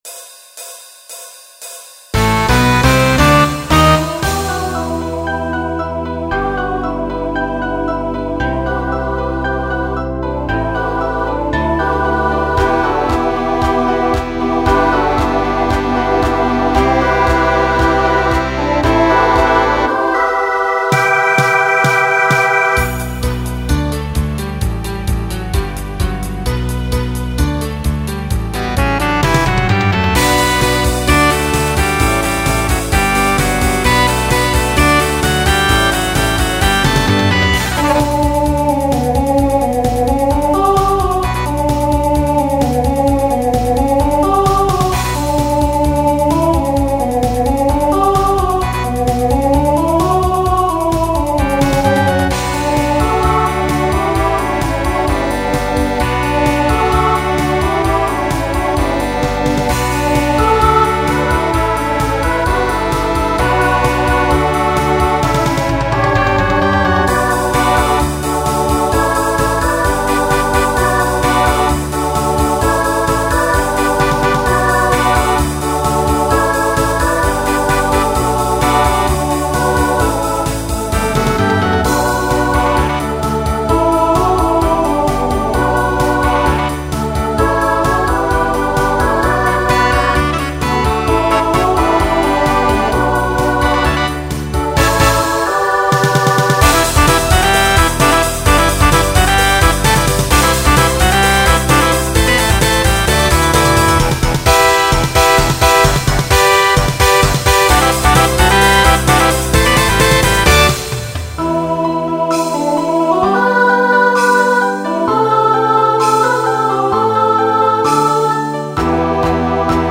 Genre Pop/Dance , Rock Instrumental combo
Voicing SATB